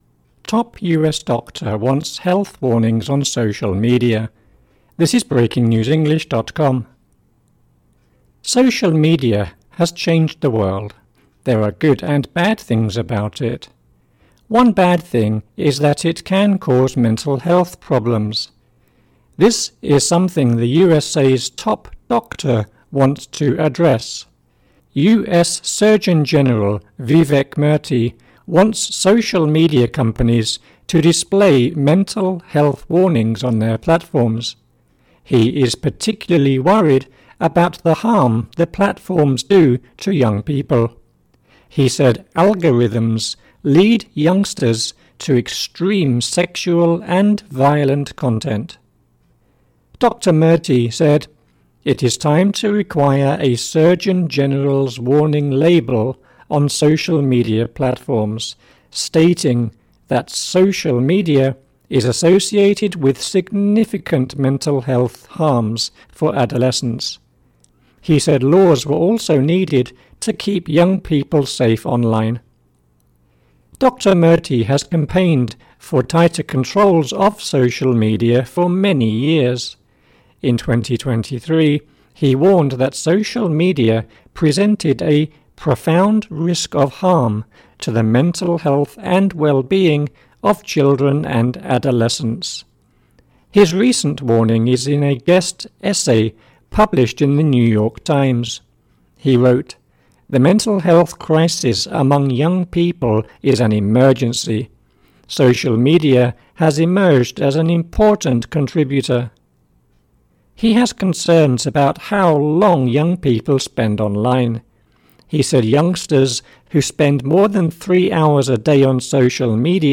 AUDIO (Slow)